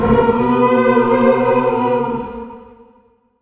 Random Melodic Choir